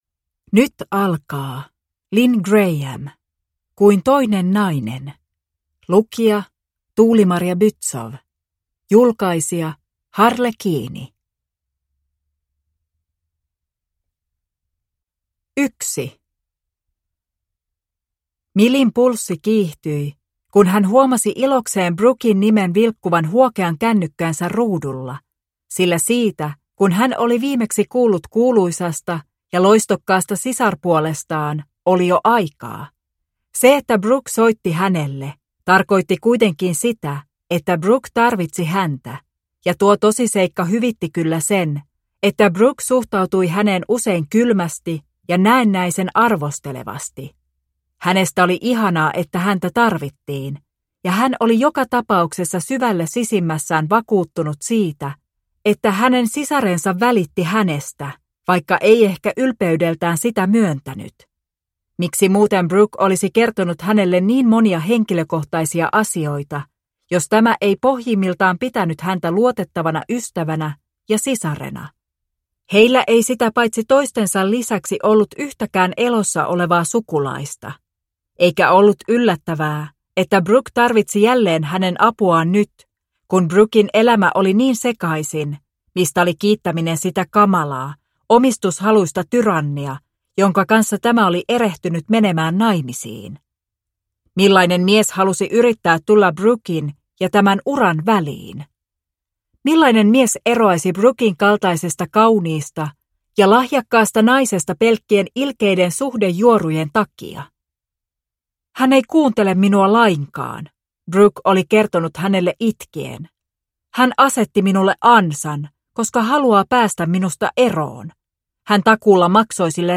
Kuin toinen nainen (ljudbok) av Lynne Graham